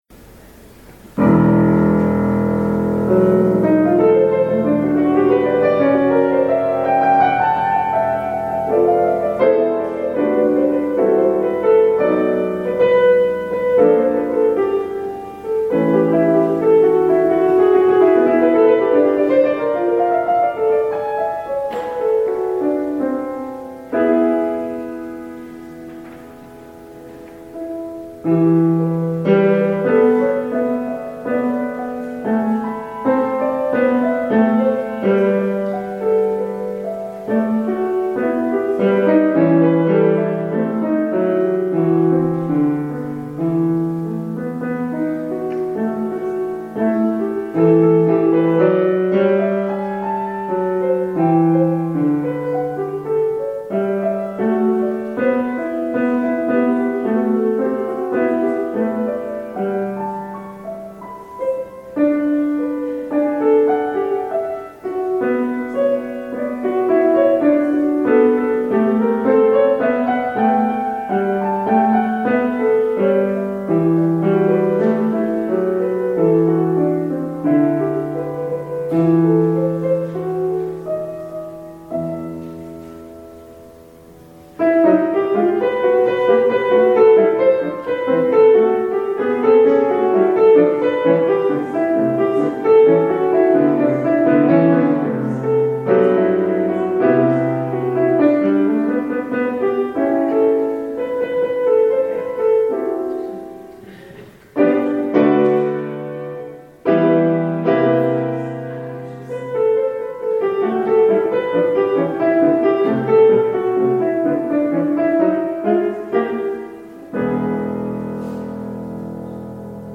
Audio recording of the 4pm service.